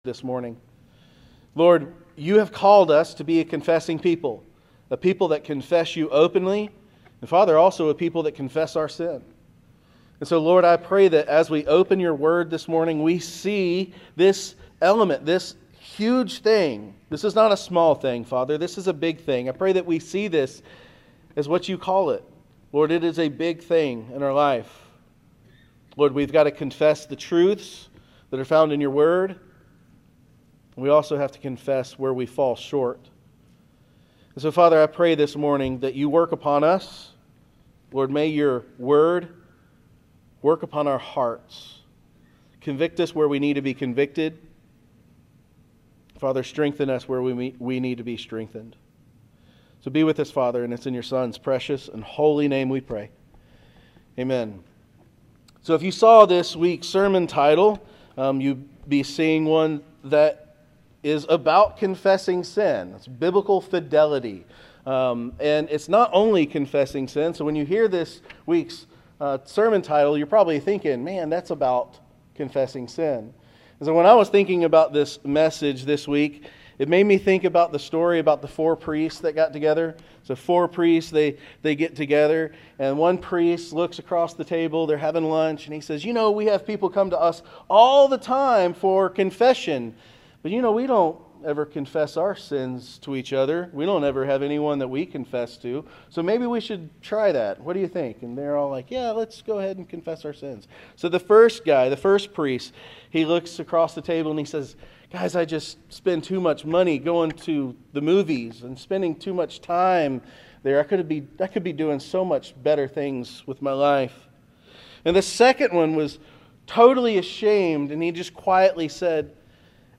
Sermons | Waleska First Baptist Church